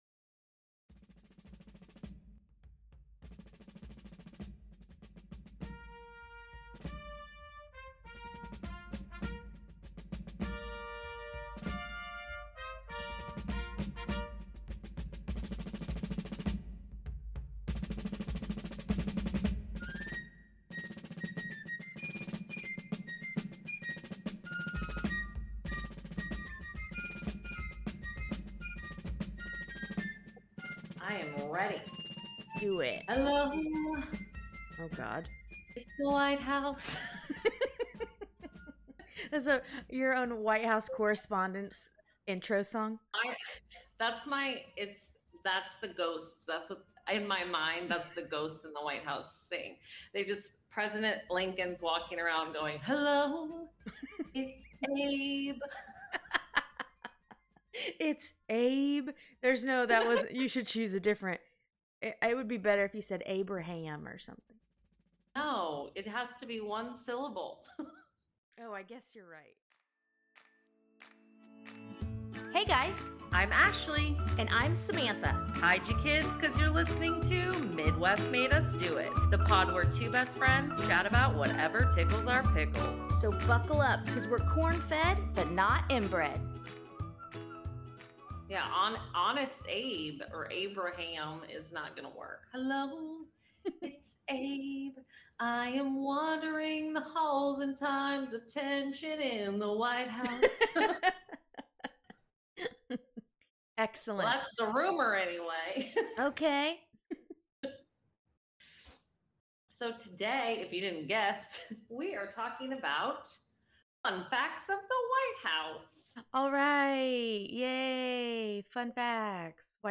The pod where two best friends chat about whatever tickles their pickle.